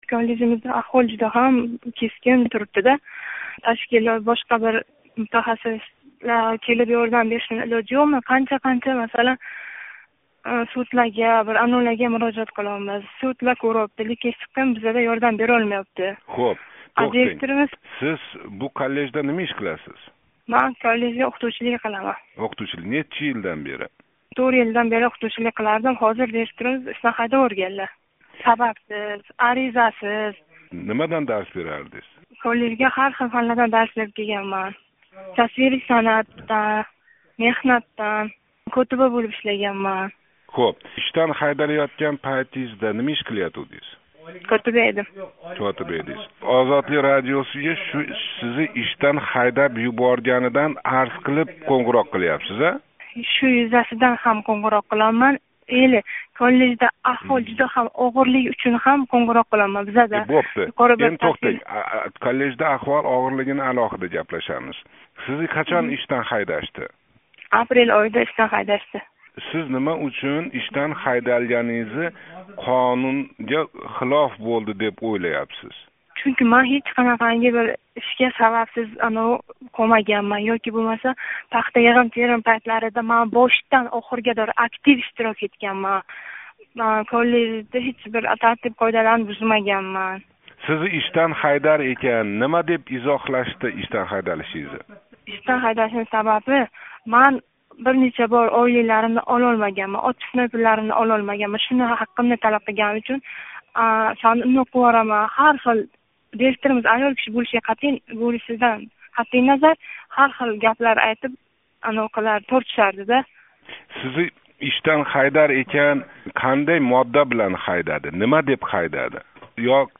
Китоб иқтисодиёт коллежи ўқитувчиси билан суҳбат